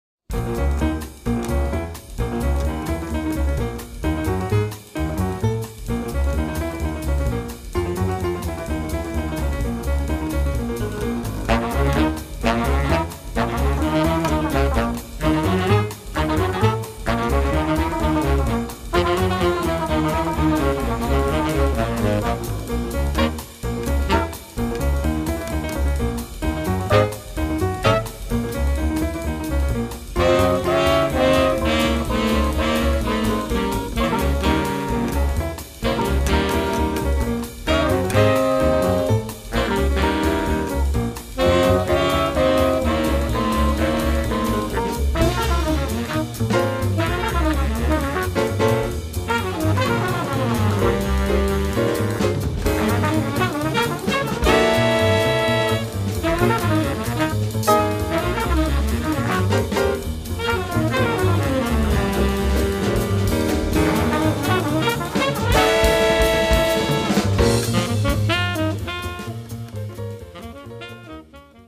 Les musiciens du quintet:
Les voici écourtés et dans l'ordre du CD.